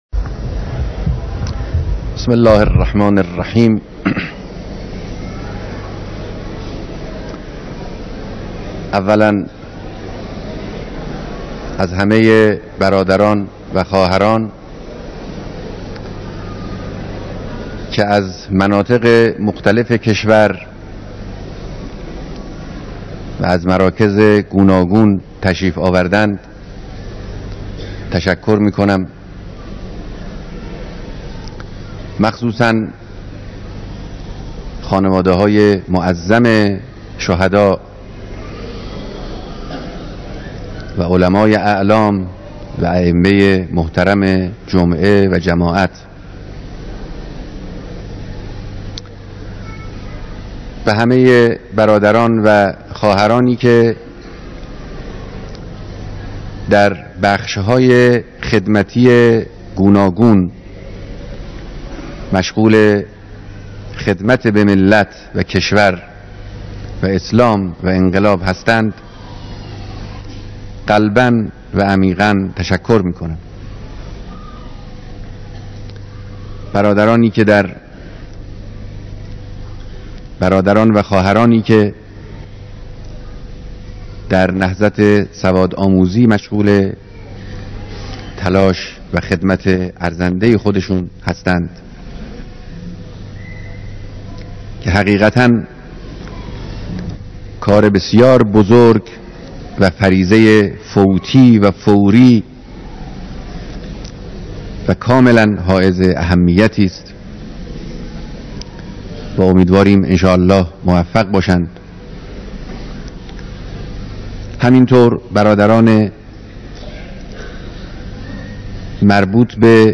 بیانات رهبر انقلاب در دیدار اقشار مختلف مردم سراسر کشور